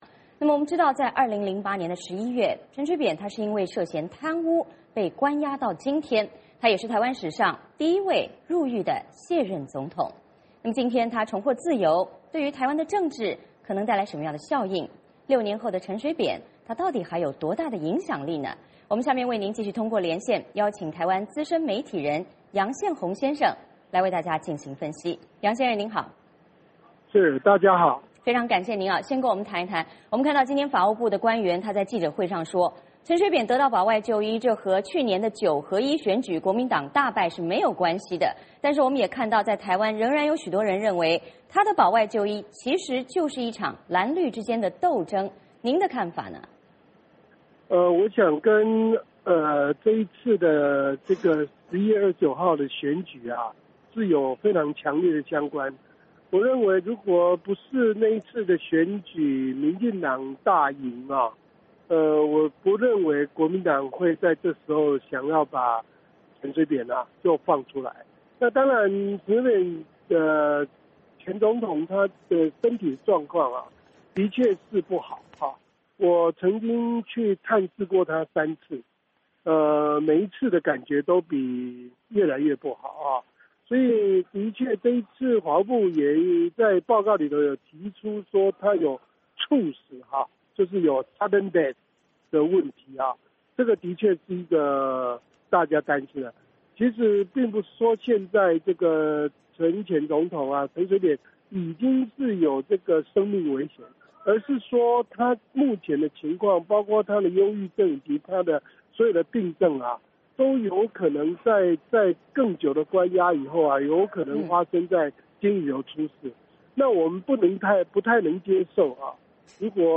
VOA连线：陈水扁保外就医，蓝绿和解有望？